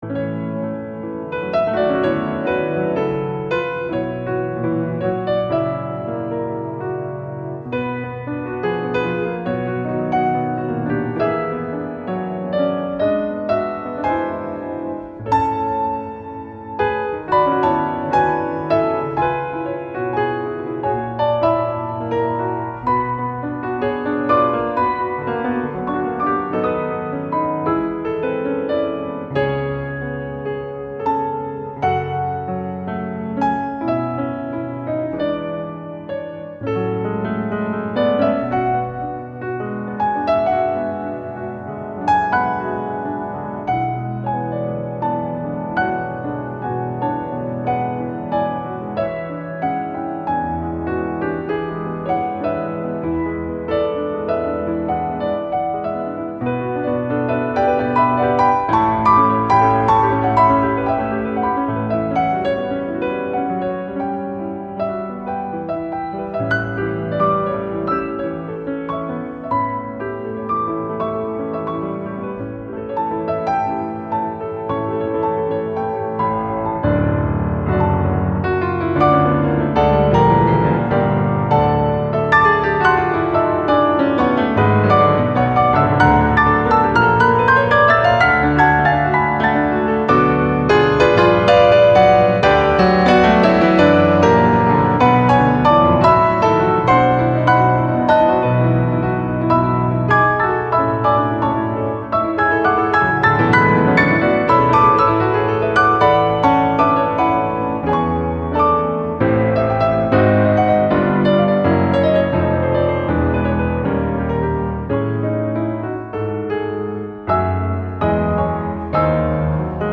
piano - classique - melancolique - nostalgique - melodique